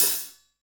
HAT H.H.LO2E.wav